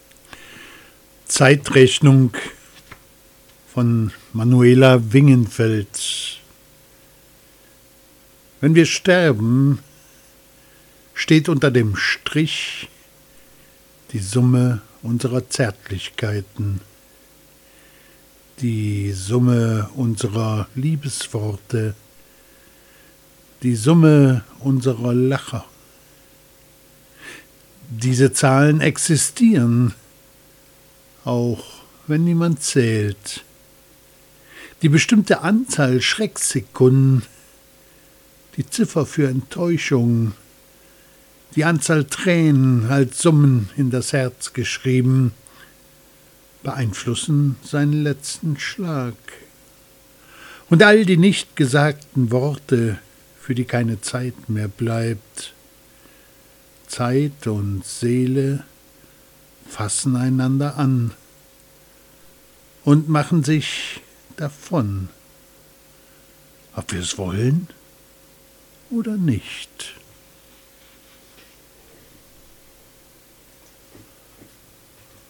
Lesungen: